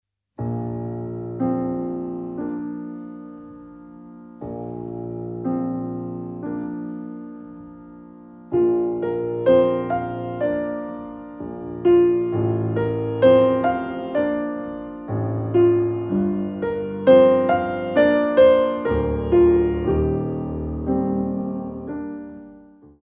Cool down & révérence